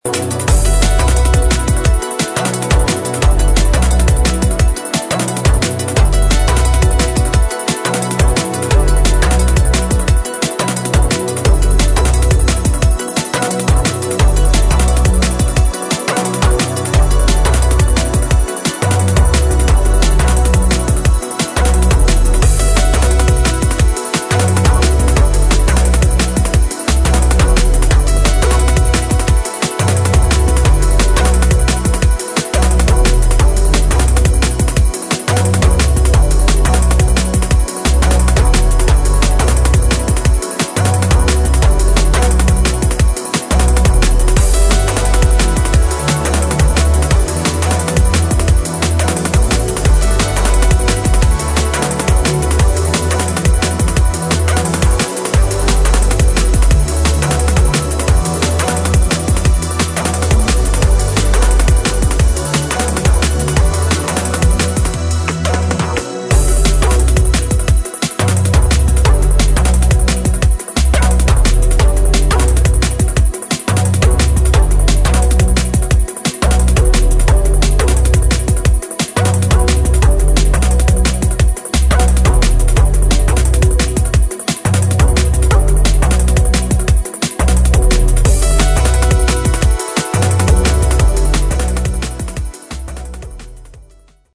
DRUM'N'BASS | JUNGLE